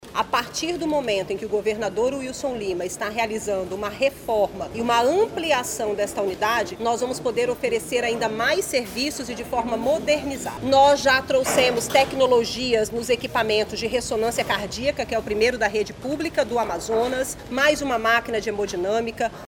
O projeto de reforma e ampliação, executado pela Unidade Gestora de Projetos Especiais (UGPE), está atualmente com 22% de conclusão e tem previsão de término para 2026, com um investimento total de R$ 43 milhões, como explica a titular da Secretaria de Estado de Saúde (SES-AM), Nayara Maksoud,
SONORA02_NAYARA-MAKSOUD-.mp3